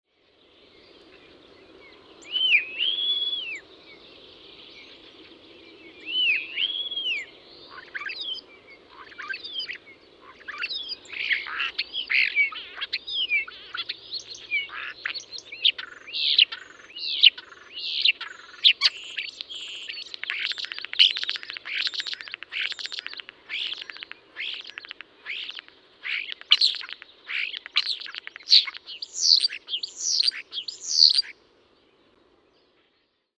Muuttolintukalenteri: Kottarainen löytää aina uusia säveliä
Äänekäs laulaja osaa matkia taitavasti kuulemiaan ääniä.